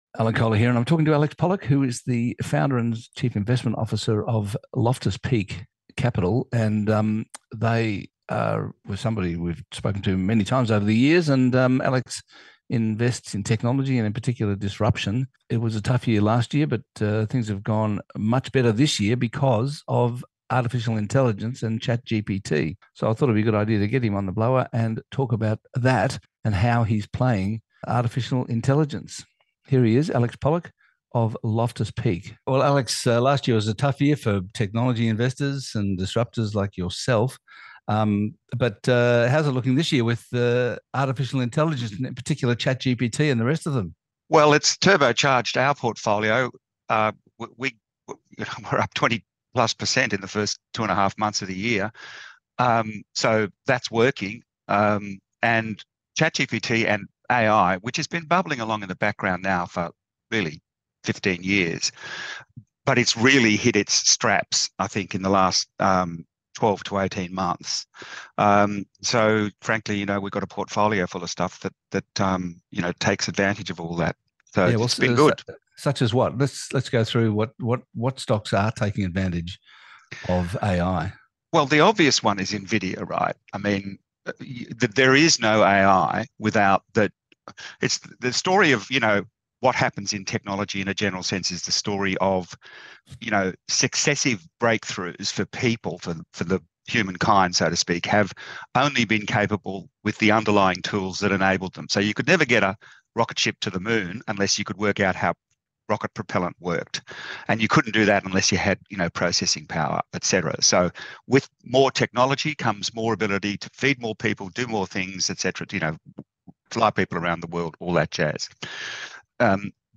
Eureka Report in conversation